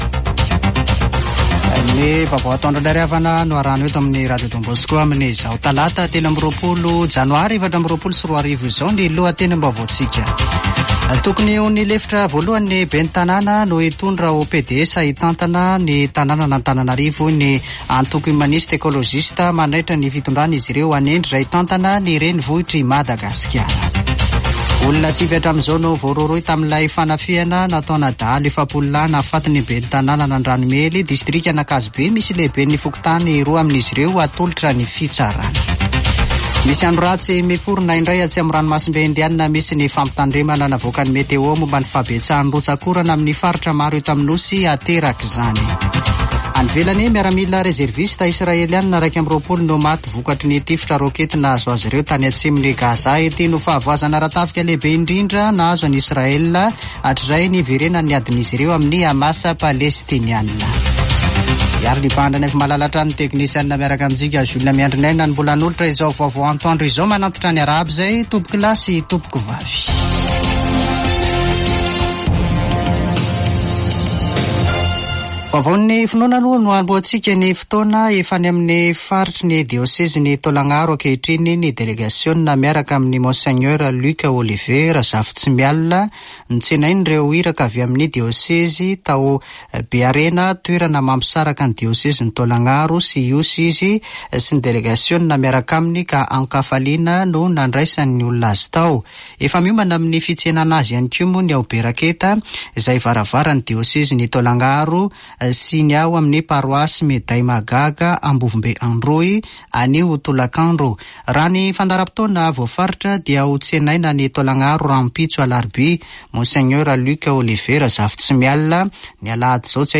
[Vaovao antoandro] Talata 23 janoary 2024